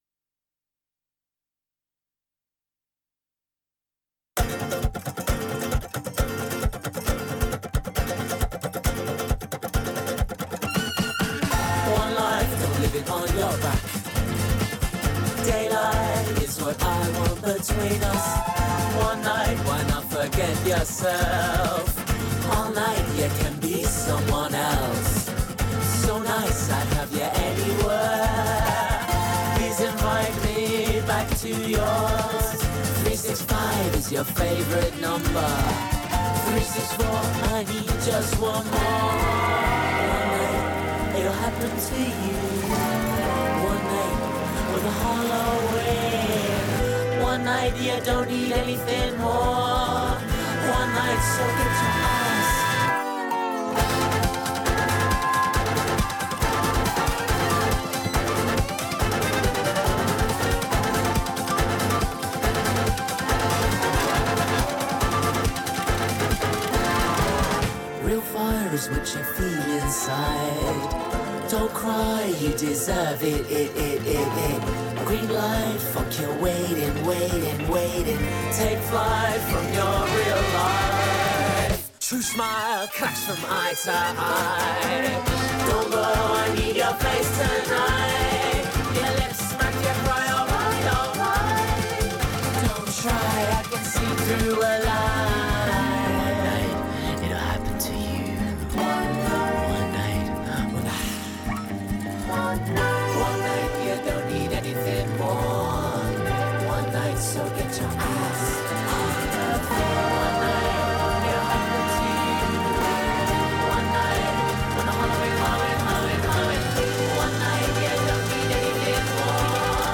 Top Of The Pôle c’est l’émission mensuelle du pôle-programmation qui cherche et vous propose tous les mois des nouveautés musicales pour que vos oreilles aient des nouveaux sons à écouter sur Radio Campus Grenoble. C’est le moment où on vous partage à l’antenne nos derniers morceaux coups de cœur, coups de rage et autres émotions musicales…